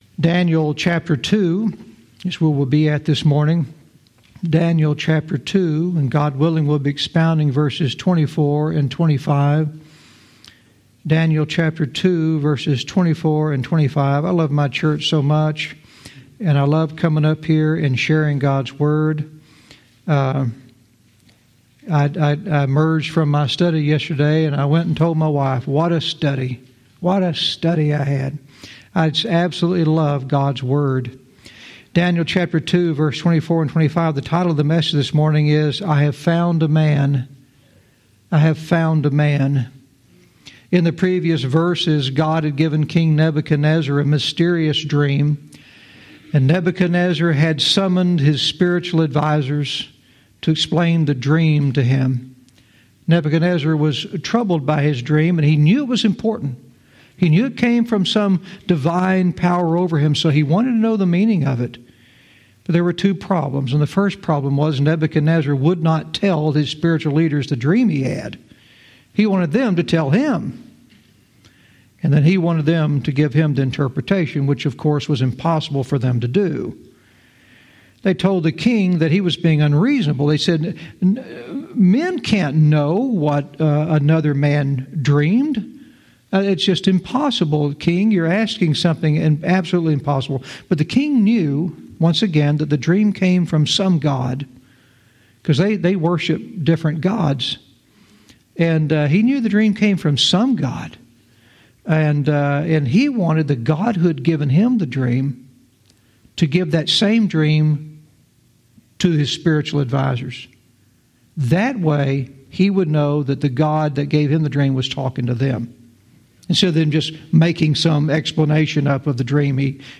Verse by verse teaching - Daniel 2:24-25 "I Have Found a Man!"